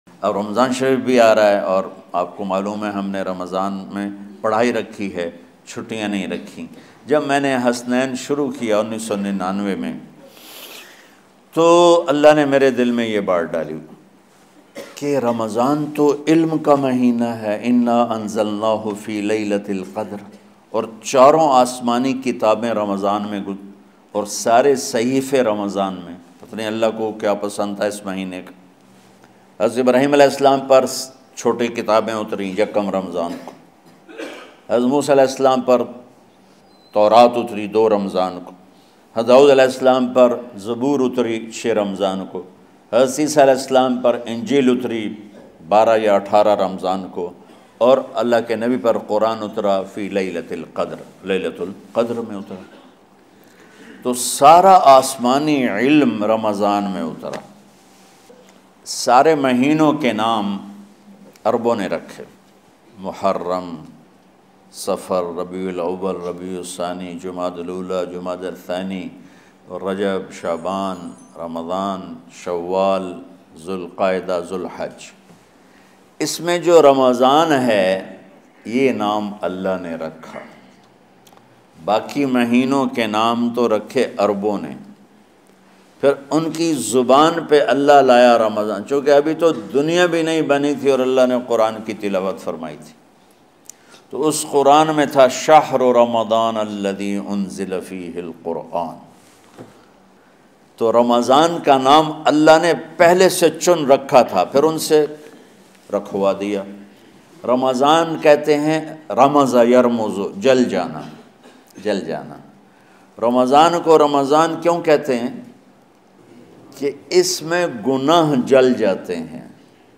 Ramadan Main Paish Aane Wale Waqiat Molana Tariq Jameel Latest Bayan
Tariq Jameel, commonly referred to as Maulana Tariq Jameel, is a Pakistani religious and Islamic scholar, preacher, and public speaker from Tulamba near Mian Channu in Khanewal, Punjab in Faisalabad, Pakistan.